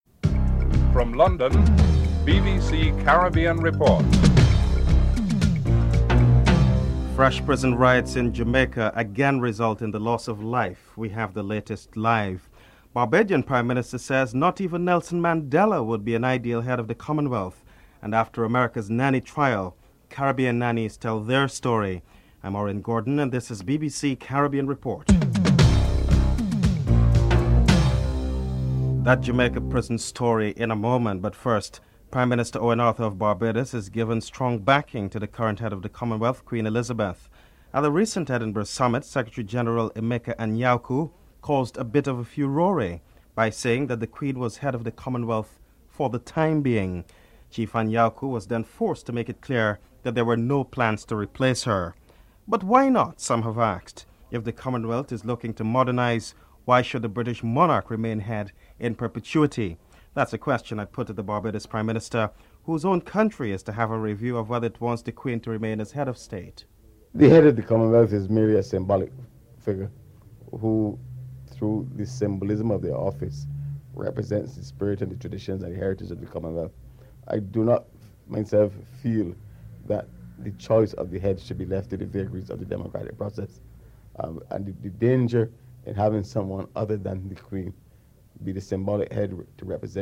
1. Headlines (00:00-00:27)
2. Barbados Prime Minister Owen Arthur says that not even Nelson Mandela would be an ideal Head of the Commonwealth. Prime Minister Owen Arthur is interviewed (00:28-03:47)